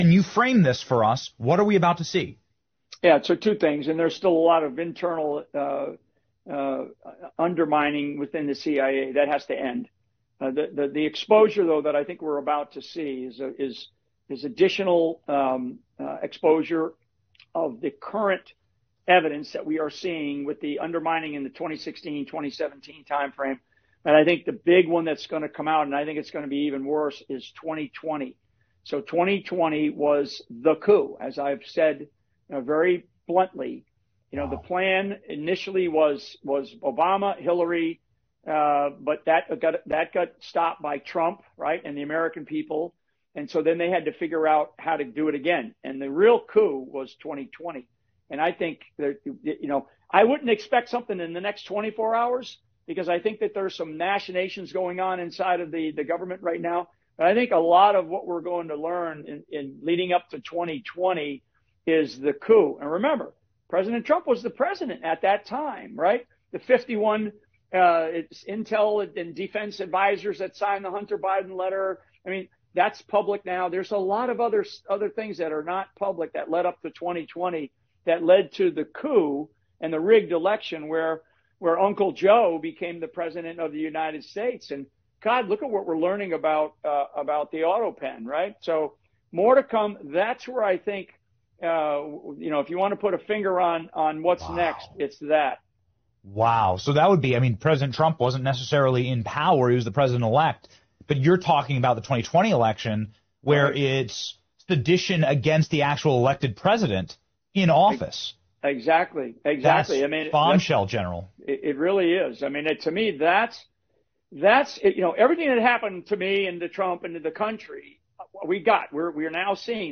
במהלך ראיון סוער, נטען כי הבחירות לנשיאות ארהב בשנת 2020 היו למעשה הפיכה מתוכננת, בה מעורבים גורמים מתוך ה-CIA וממסד הביטחון האמריקאי. המרואיין מתאר כיצד כבר בשנים 2016-2017 החלה פעילות פנימית לערער את הנשיא טראמפ, אך השיא הגיע בבחירות 2020, שלדבריו היו הפיכה אמיתית שהובילה להחלפת השלטון.